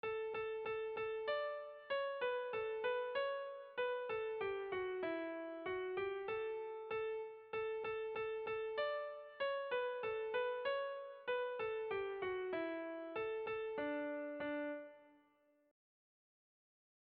Erromantzea
Lauko handia (hg) / Bi puntuko handia (ip)
A1A2